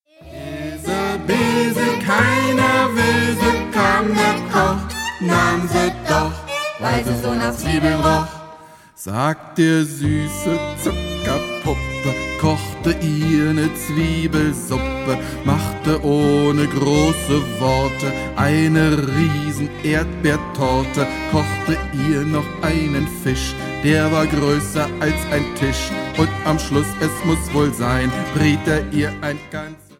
Kinder- / Jugendbuch Gedichte / Lieder